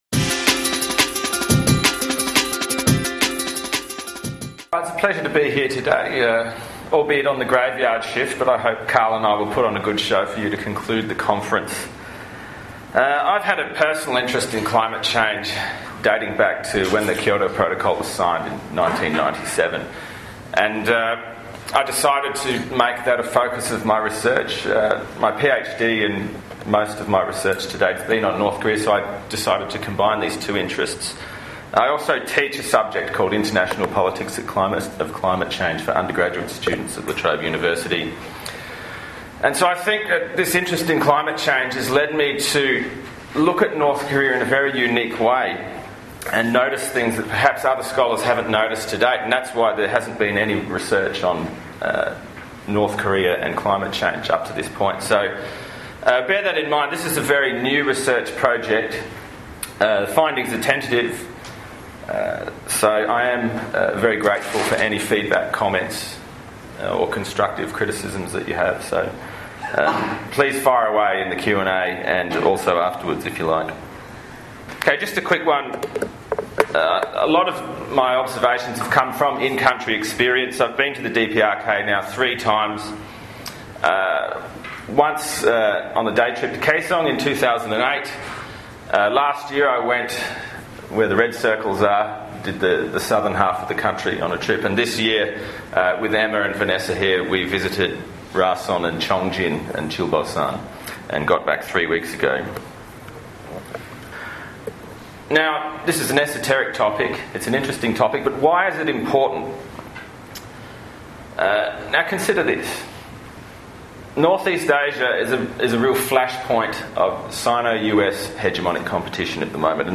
Korean Studies Association of Australasia – 8th Biennial Conference, 27-28 June 20113, Australian National University, Canberra.